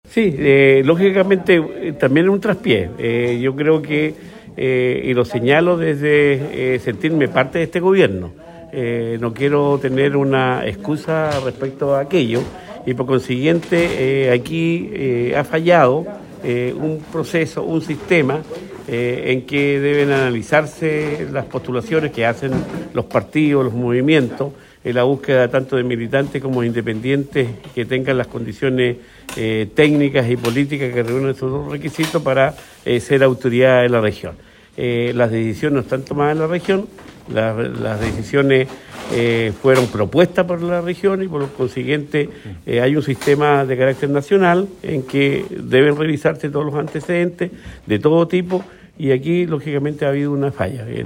En ese sentido y ante lo sucedido con la fallida designación de la SEREMI de las Culturas, Karin Müller, el Gobernador Regional, Luis Cuvertino (PS), dijo que esto se trataría de un nuevo traspié para el Gobierno del Presidente Gabriel Boric en la región y que ha fallado el sistema implementado para la revisión de antecedentes para el nombramiento de autoridades.